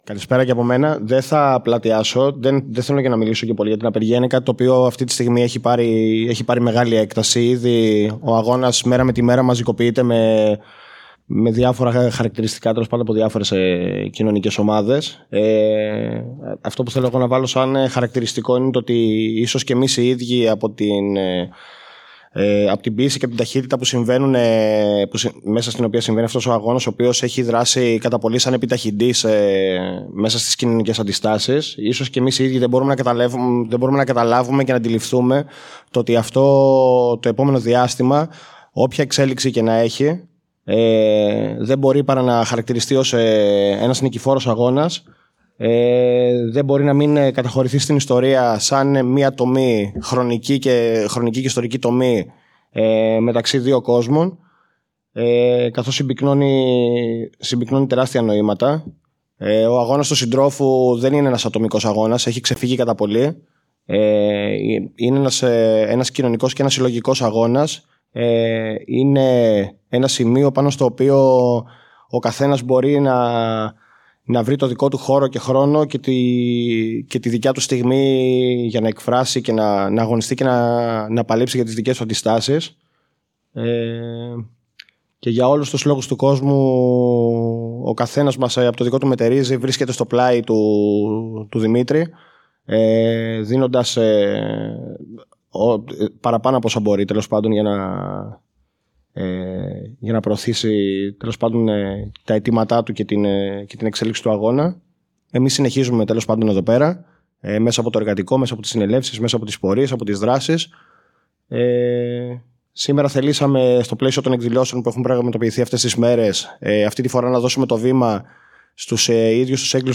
Το ηχητικό αρχείο της τηλεφωνικής επικοινωνίας με τους/την κρατούμενους/η:
για την απεργία πείνας του Δημήτρη Κουφοντίνα, που πραγματοποιήθηκε την Πέμπτη 4 Μαρτίου 2021 στο κατειλημμένο Εργατικό Κέντρο Θεσσαλονίκης και αναμεταδόθηκε ζωντανά από το Eλεύθερο Κοινωνικό Ραδιόφωνο 1431AM.